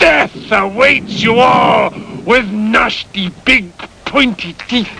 Amiga 8-bit Sampled Voice
Fire.mp3